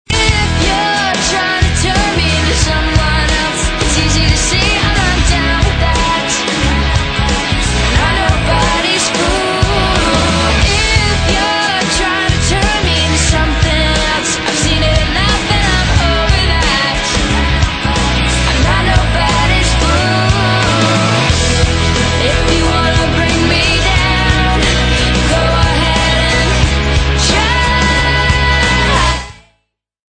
Pop & Rock